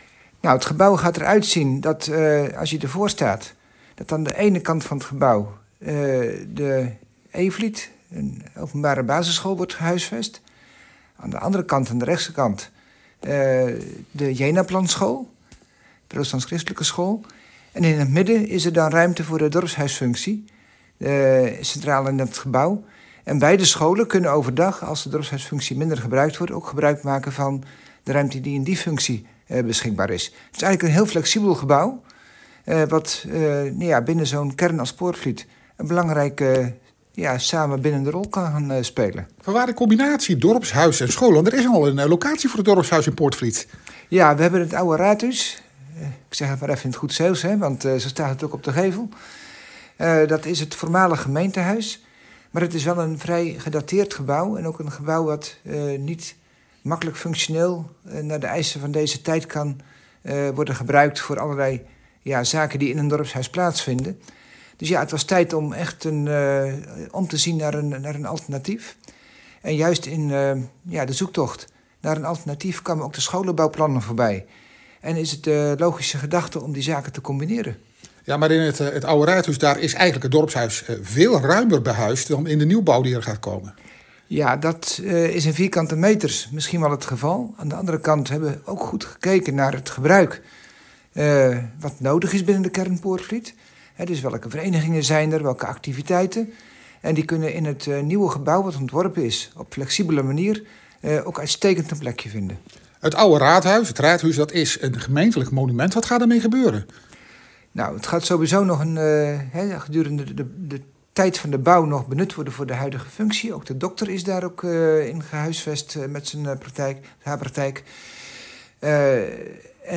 Audio: Wethouder Peter Hoek over het ontwerp van de MFA Poortvliet.
Peter_Hoek_MFA-Poortvliet_ZWUpdate.mp3